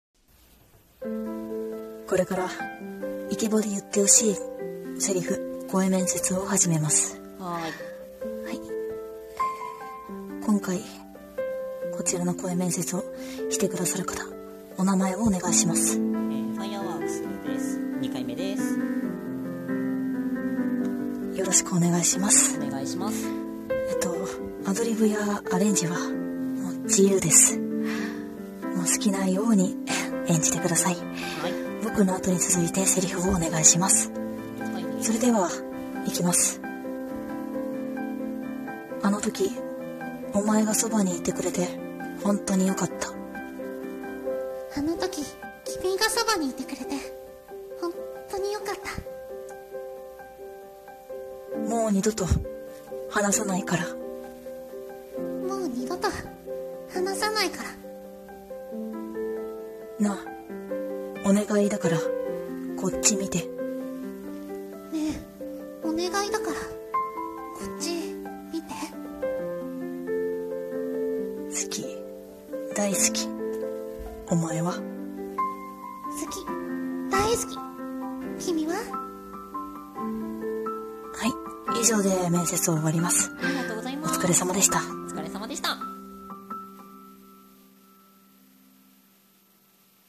[声面接]イケボで言ってほしい台詞。